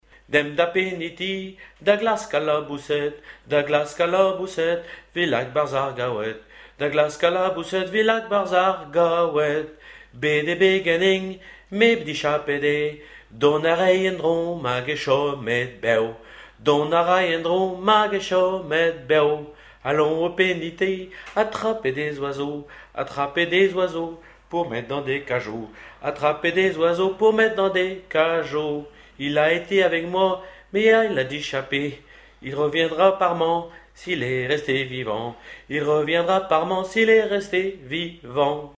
Genre brève
Témoignages et chansons
Pièce musicale inédite